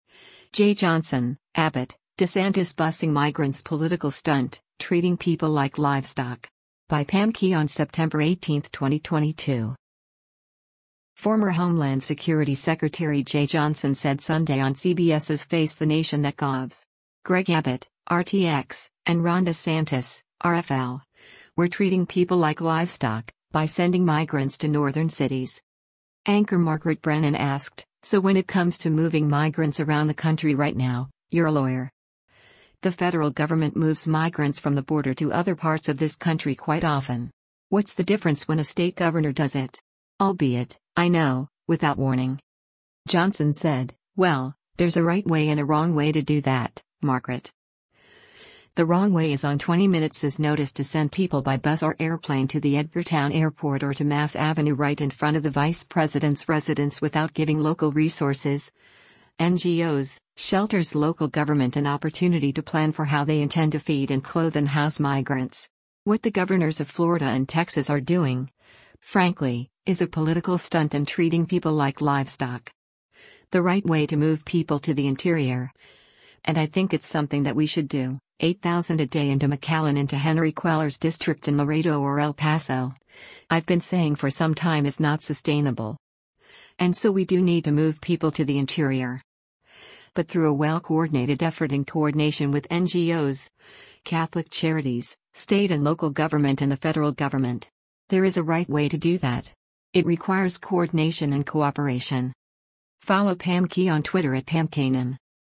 Former Homeland Security Secretary Jeh Johnson said Sunday on CBS’s “Face the Nation” that Govs. Greg Abbott (R-TX) and Ron DeSantis (R-FL) were “treating people like livestoc,” by sending migrants to northern cities.